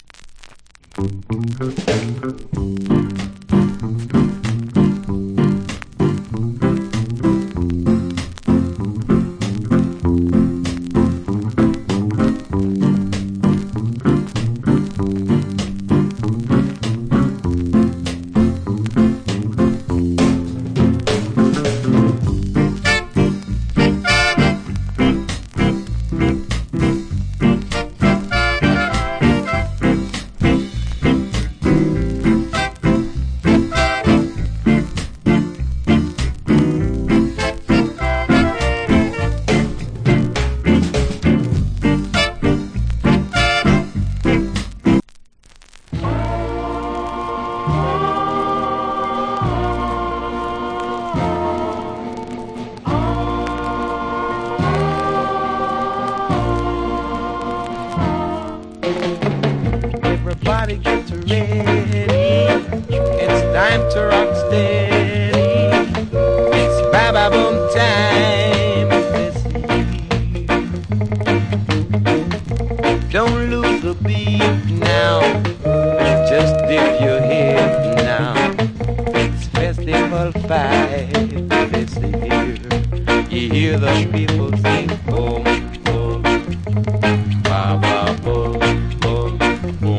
Wicked Rock Steady Inst.